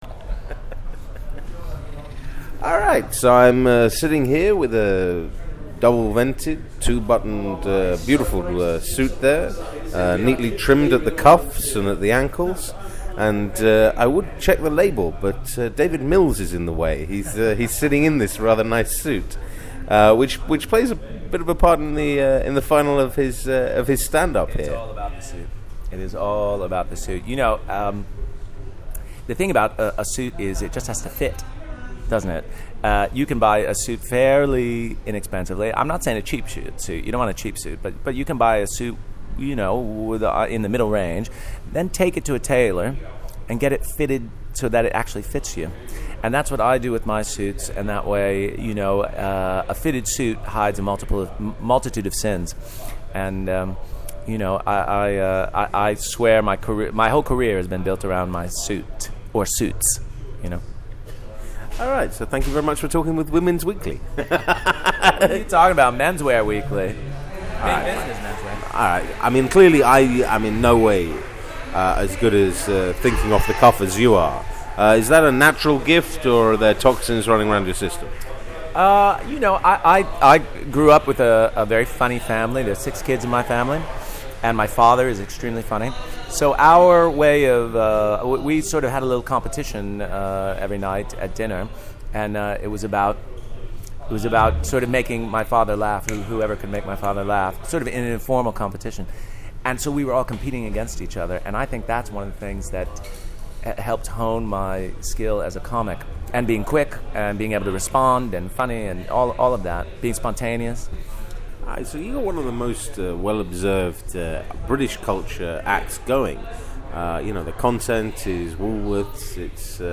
Edinburgh Audio 2013